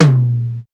Tr8 Tom 01.wav